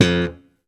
JS POP #2 F3.wav